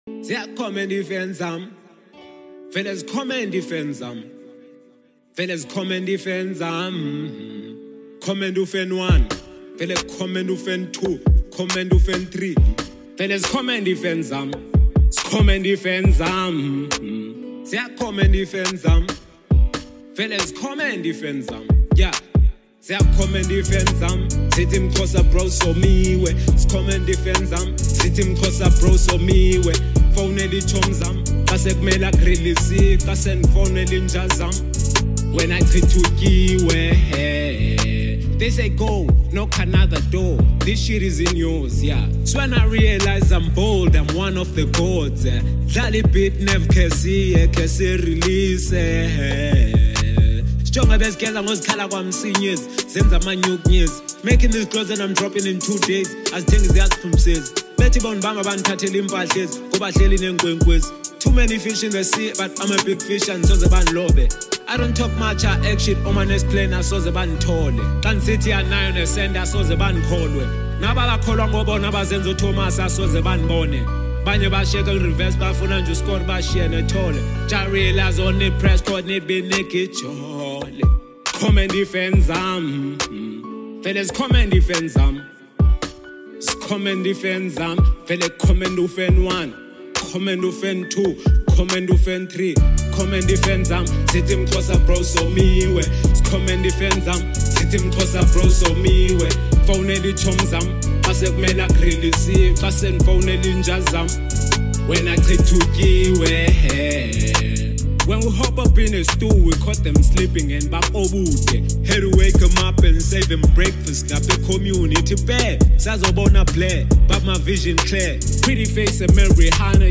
vibrant new track